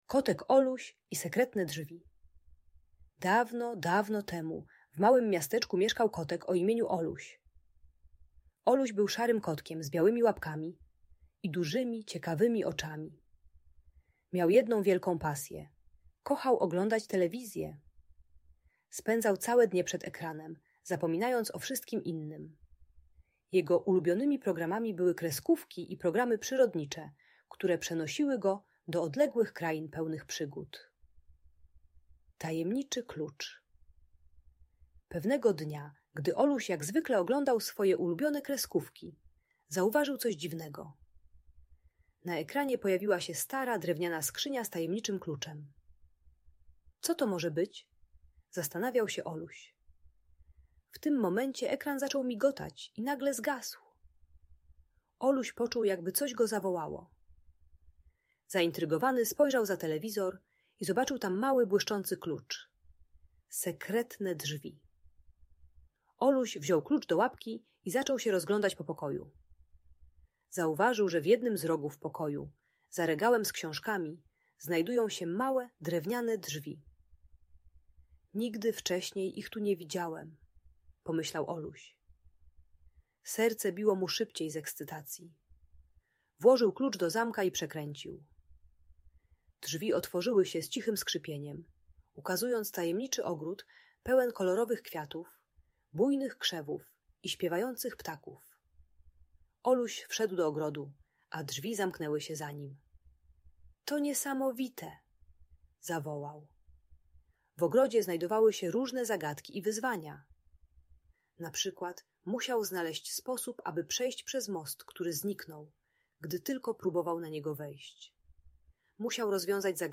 Kotek Oluś i Sekretne Drzwi - Bajki Elektronika | Audiobajka